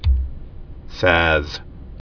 (săz)